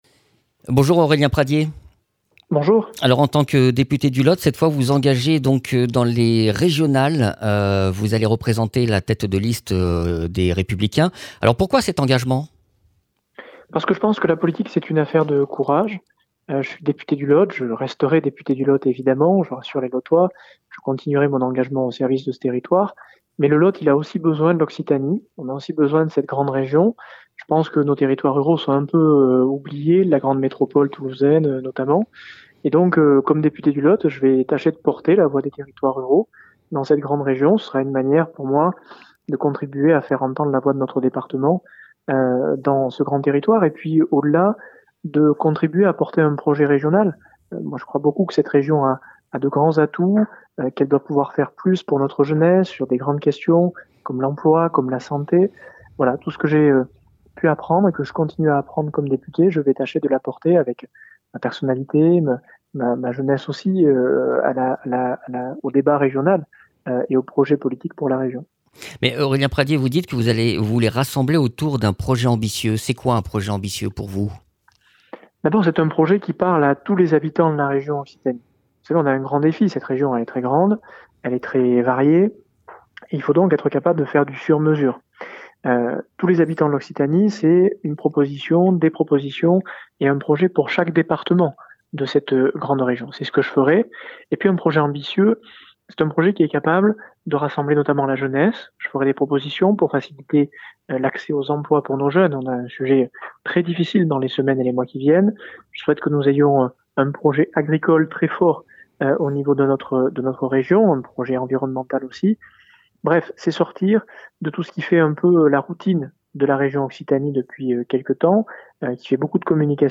Interviews
Invité(s) : Aurélien Pradié, député LR du Lot